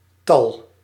Ääntäminen
Tuntematon aksentti: IPA: /nɔ̃bʁ/